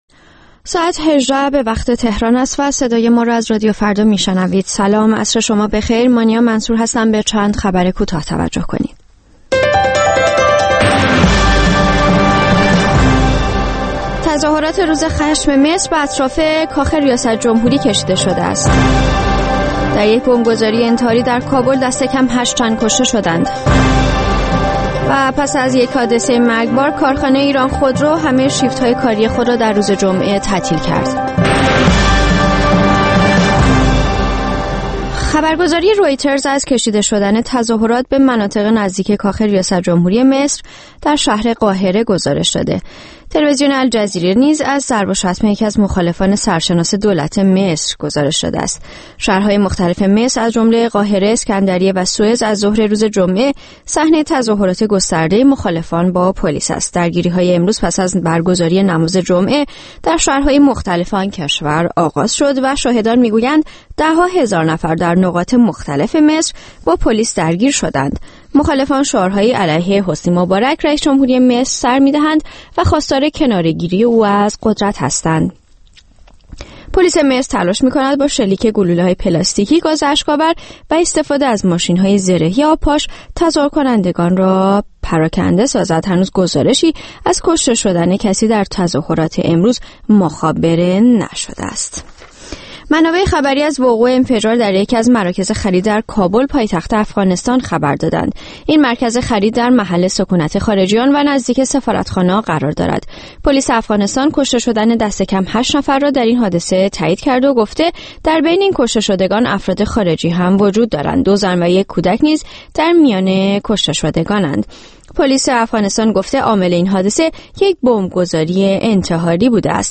با تماس‌های مستقیم و زنده شما به بحث بگذاریم.